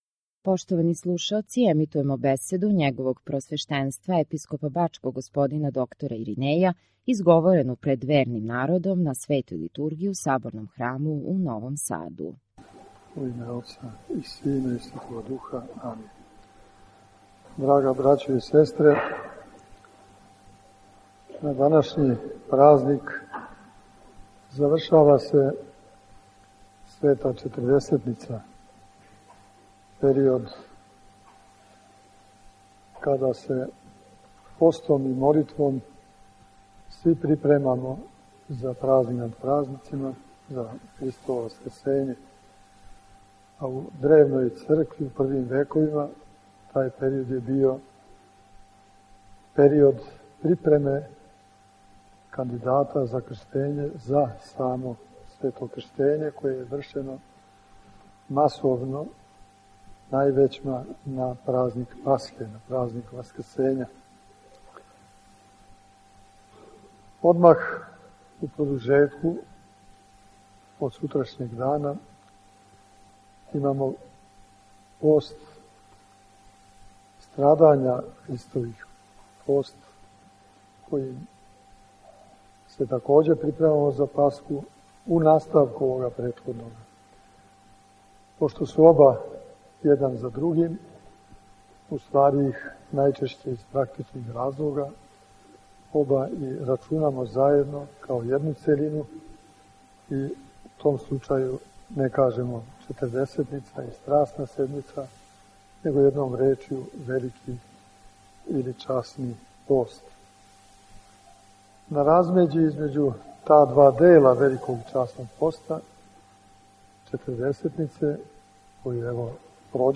У својој празничној омилији г. Епископ је упутио молитвене жеље Господу да и све нас уведе у Царство Небеско.